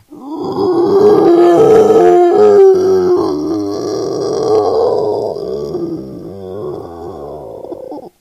zombie_idle_4.ogg